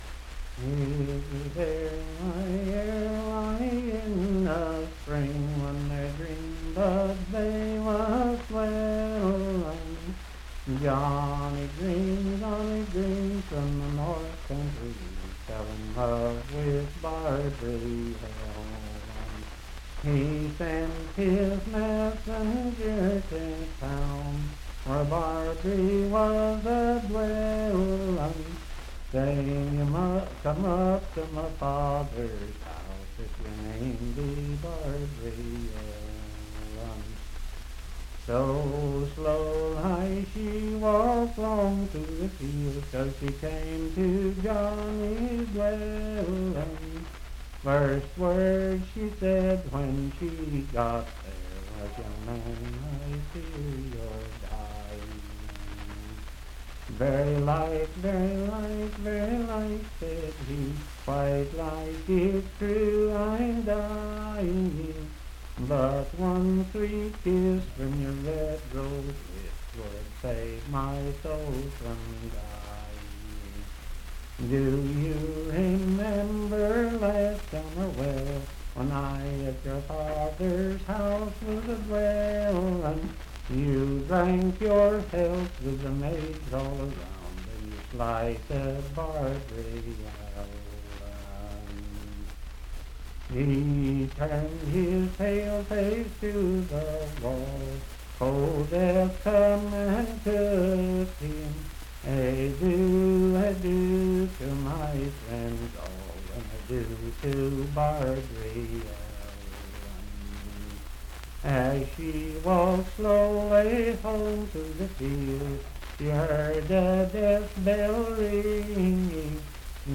Unaccompanied vocal music
in Dryfork, WV
Voice (sung)